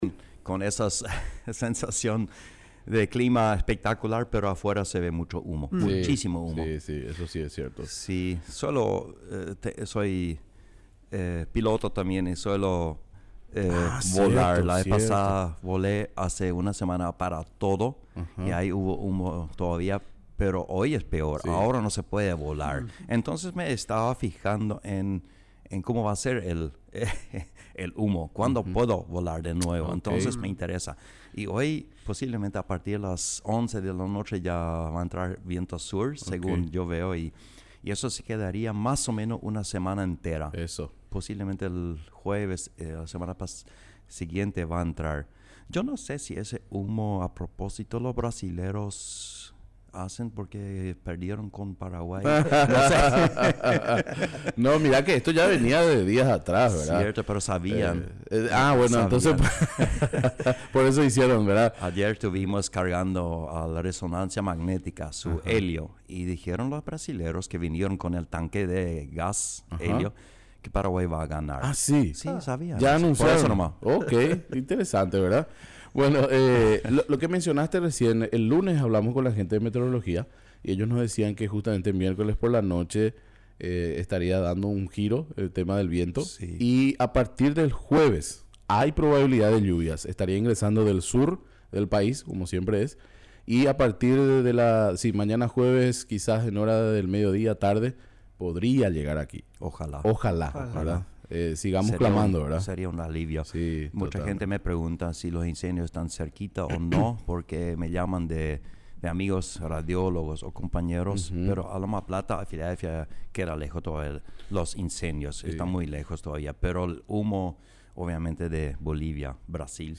Entrevistas / Matinal 610 Corre caminata- corrida de la juventud Sep 11 2024 | 00:16:59 1x 00:00 / 00:16:59 Subscribe Share RSS Feed Share Link Embed